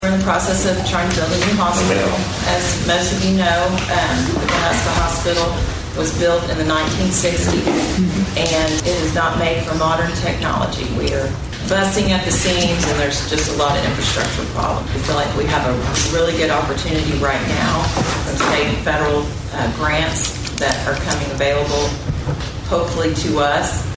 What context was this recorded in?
At Monday's Board of Osage County Commissioners meeting